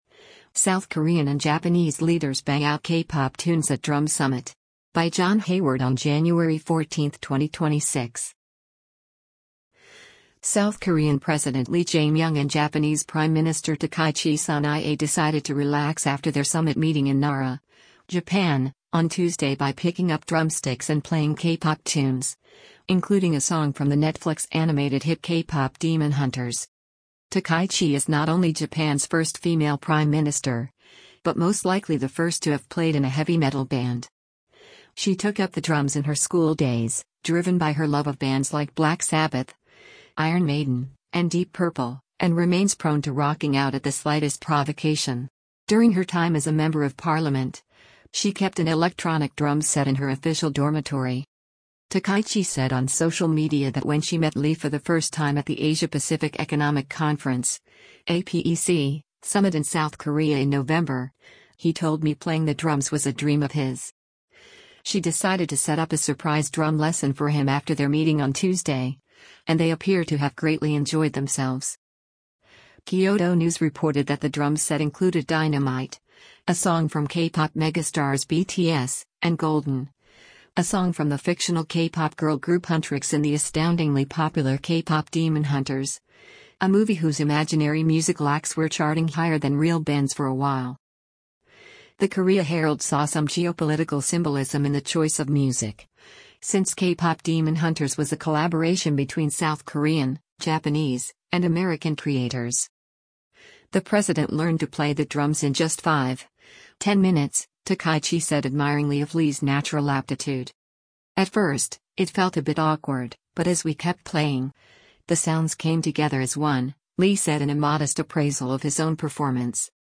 South Korean and Japanese Leaders Bang Out K-Pop Tunes at Drum Summit
South Korean President Lee Jae-myung and Japanese Prime Minister Takaichi Sanae decided to relax after their summit meeting in Nara, Japan, on Tuesday by picking up drumsticks and playing K-pop tunes, including a song from the Netflix animated hit “K-Pop Demon Hunters.”
She decided to set up a surprise drum lesson for him after their meeting on Tuesday, and they appear to have greatly enjoyed themselves:
“At first, it felt a bit awkward, but as we kept playing, the sounds came together as one,” Lee said in a modest appraisal of his own performance.